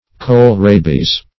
kohl-rabies - definition of kohl-rabies - synonyms, pronunciation, spelling from Free Dictionary